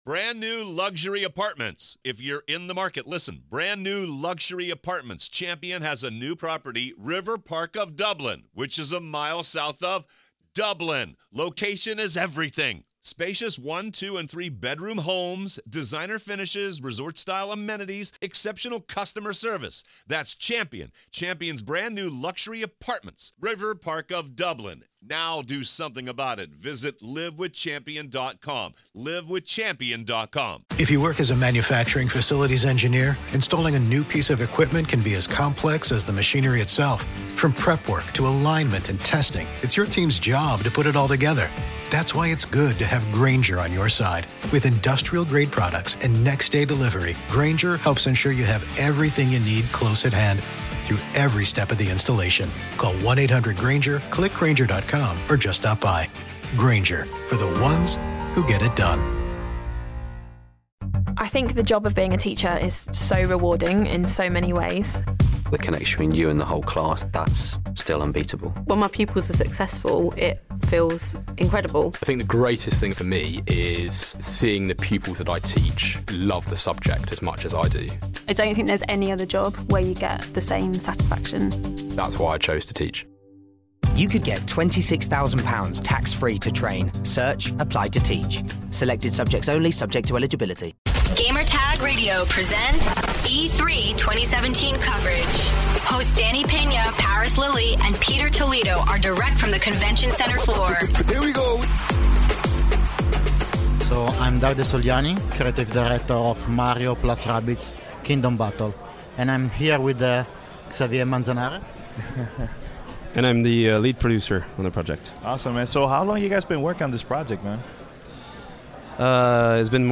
E3 2017: Mario + Rabbids Kingdom Battle Interview